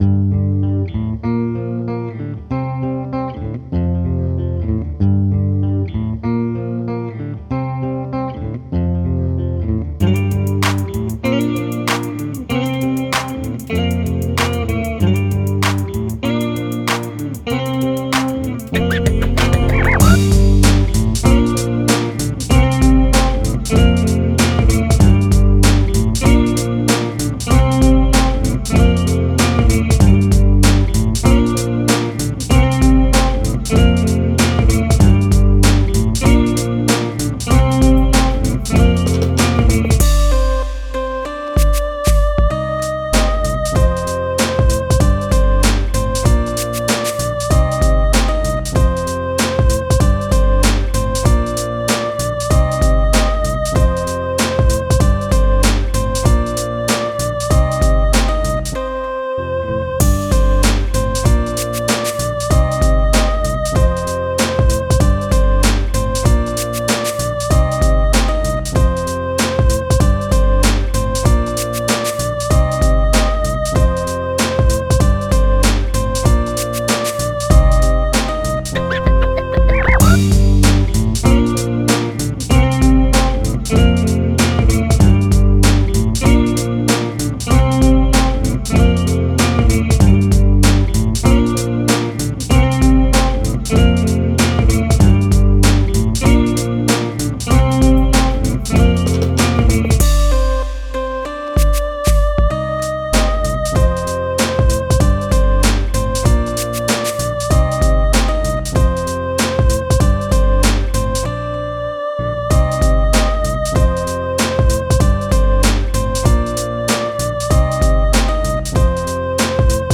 Minus Tracks, Music, Samples, Loops